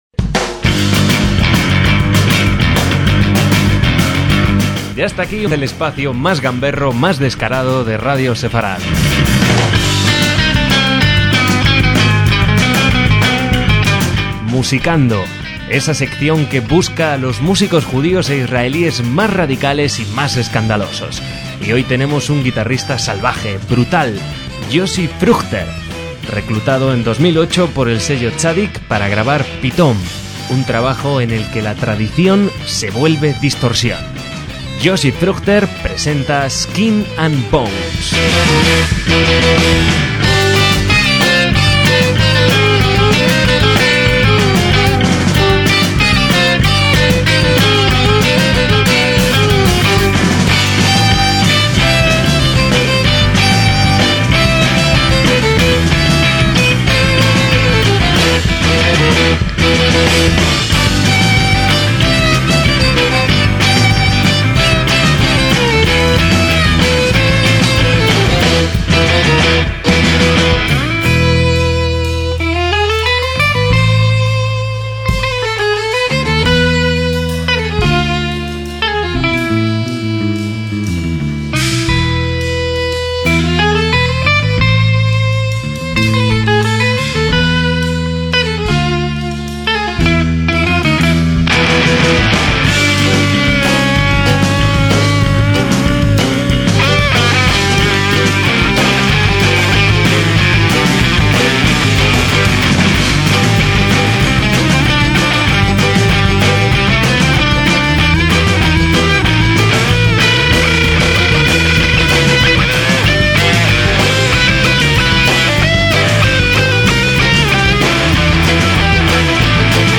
en violín
en bajo
a la batería